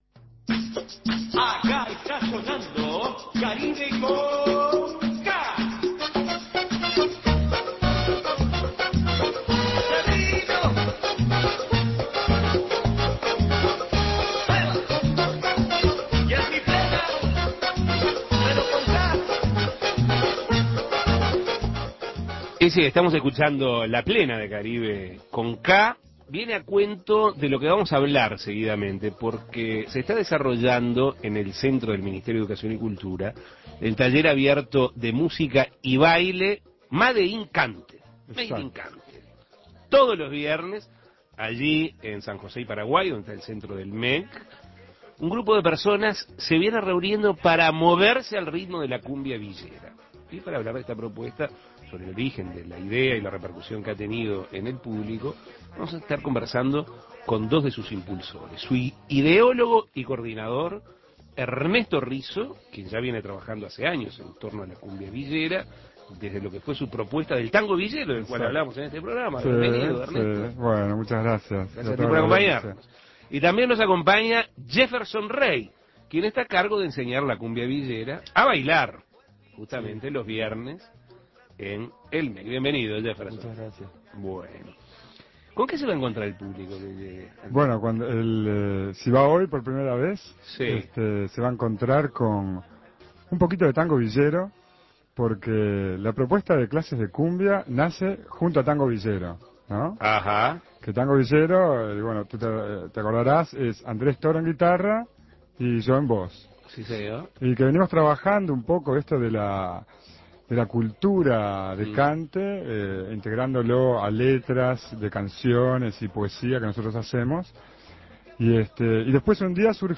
Entrevistas Made in Cante en el centro MEC Imprimir A- A A+ Desde principios de julio se desarrolla en uno de los centros MEC el taller abierto de música y baile Made in Cante.